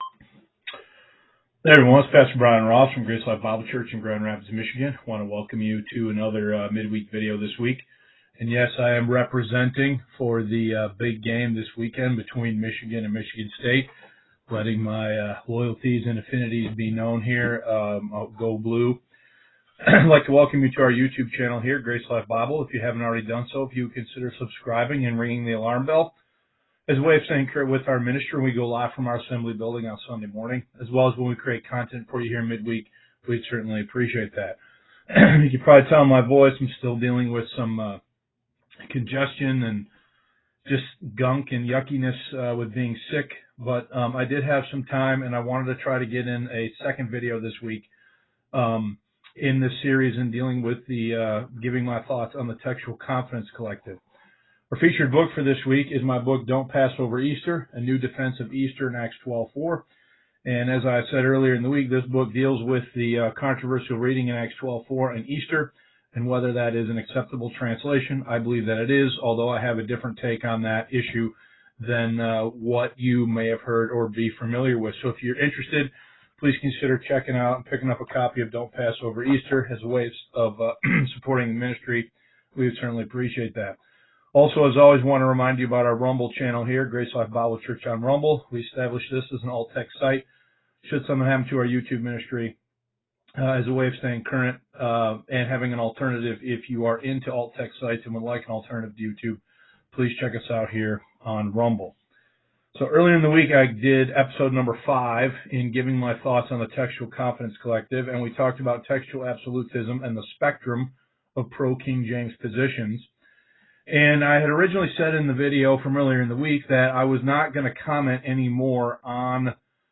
Facebook Live Videos (Vlogs)